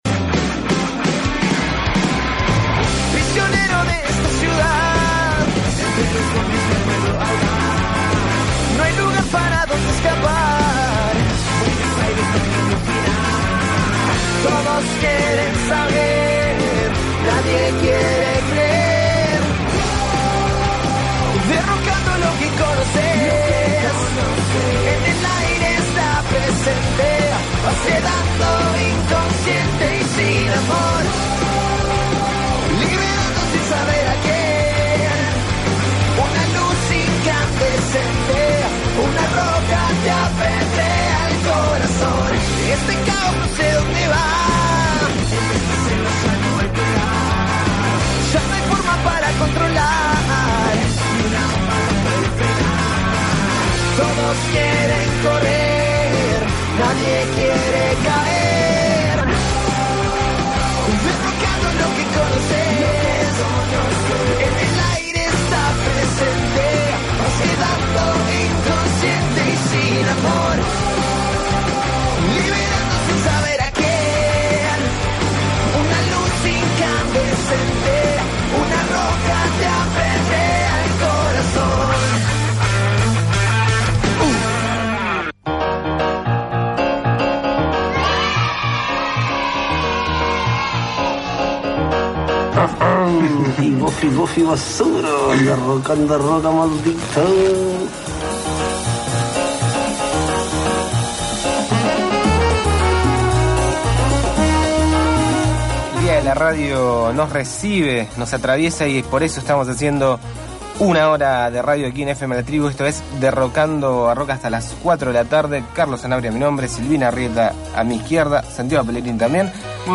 Ayer, para festejar el día de la radio, no se nos ocurrió mejor manera que hacer este bonito programa de radio para uds: